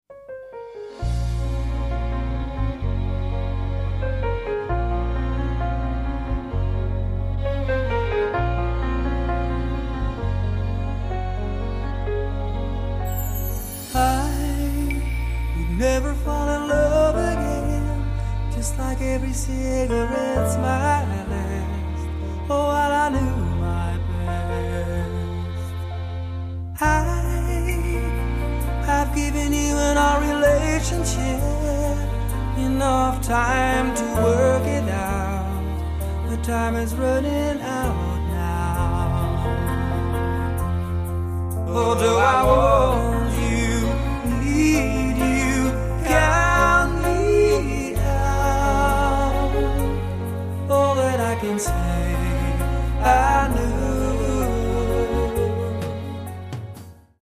a classic popballad